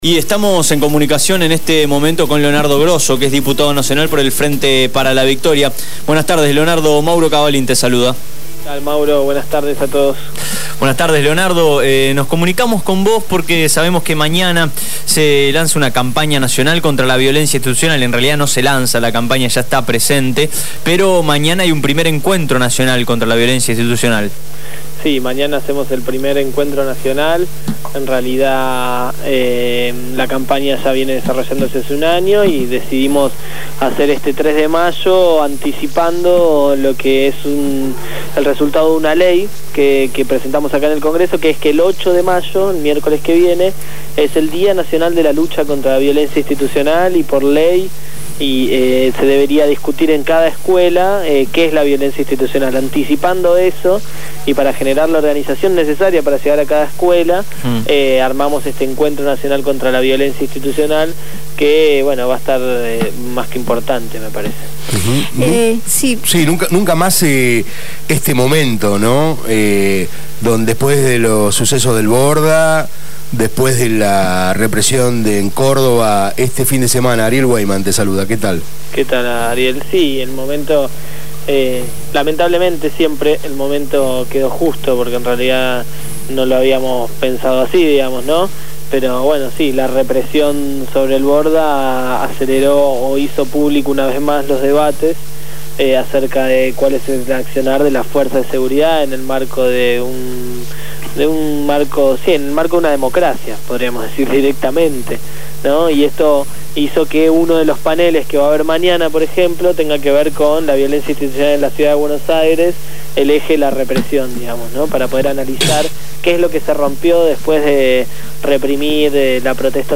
Leonardo Grosso, diputado nacional del Frente Para la Victoria y uno de los responsables de la Campaña contra la Violencia Institucional habló con Abramos la Boca.